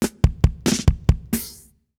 British SKA REGGAE FILL - 01.wav